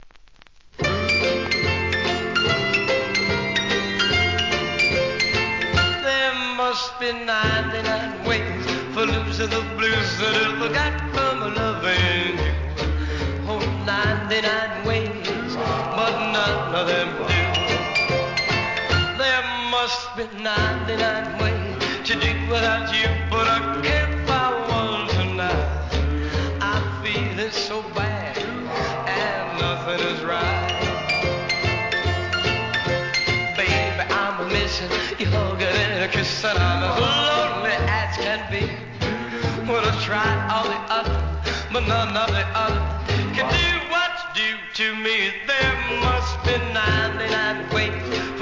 店舗 数量 カートに入れる お気に入りに追加 1957年、ミッドテンポ・ロックンロール！